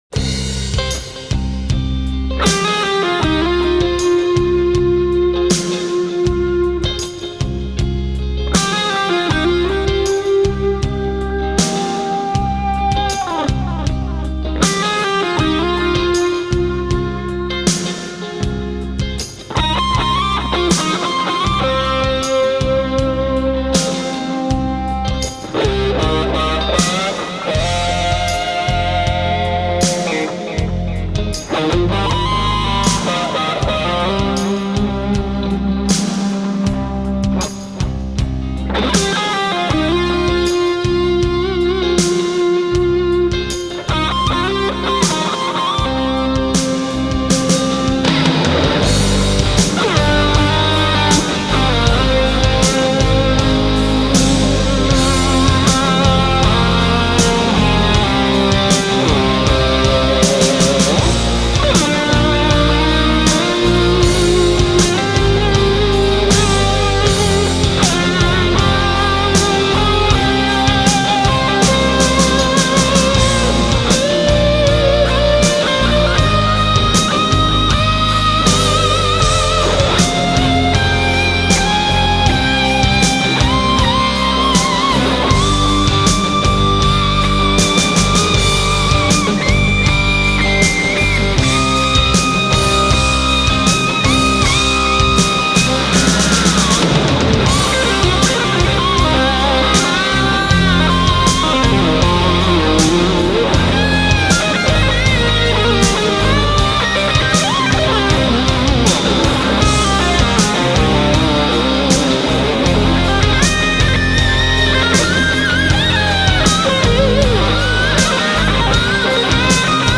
موسیقی اینسترومنتال موسیقی بیکلام
Rock